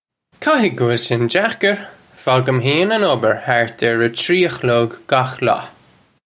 Pronunciation for how to say
Ka-hig guh will shin jak-ir! Fawgim hayn un ubbir hart urr uh tree uh khlug gakh law.
This is an approximate phonetic pronunciation of the phrase.